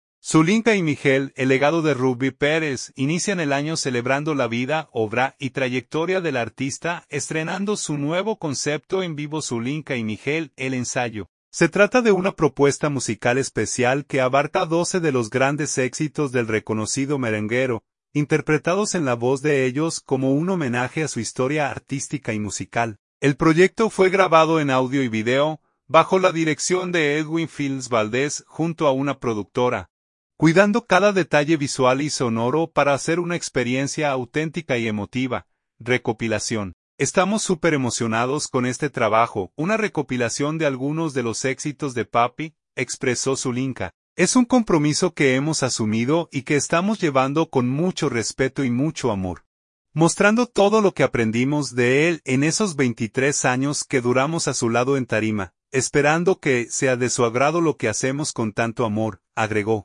nuevo concepto en vivo